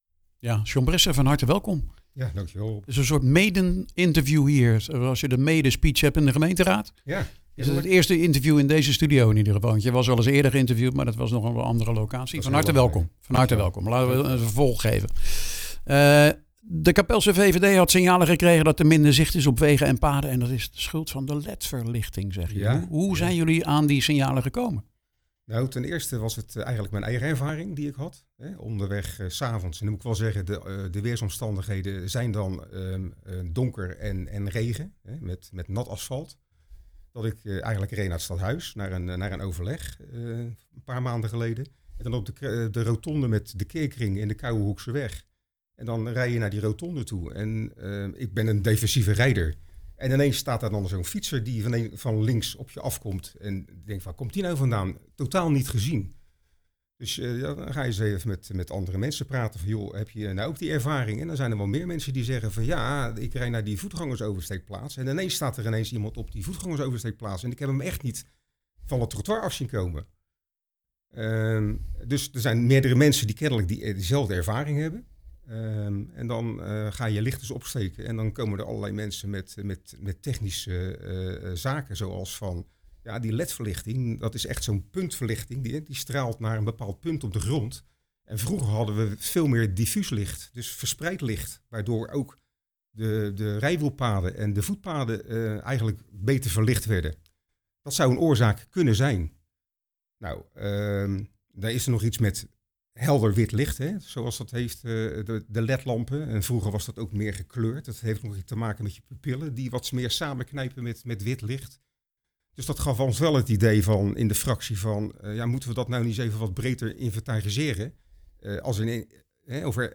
gaat daarover in gesprek